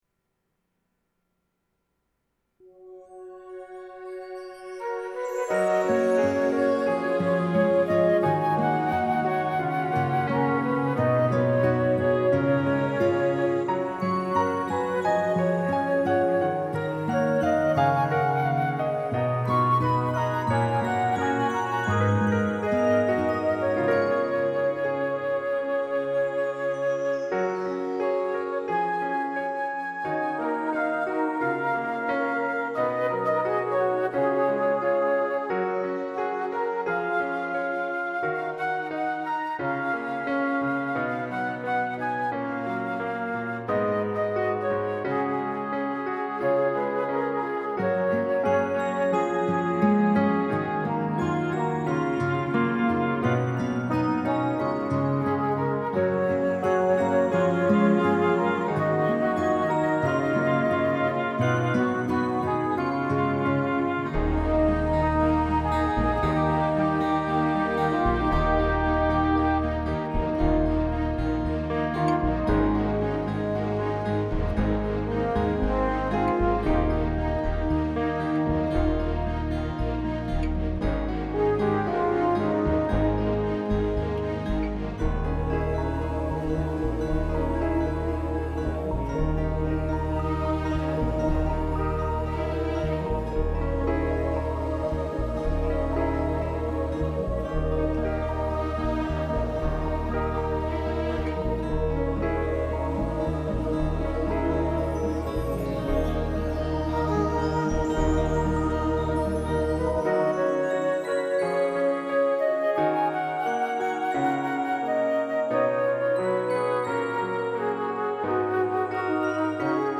We had worked on it a few months earlier; it was a moody and complicated song with two distinct rhythms.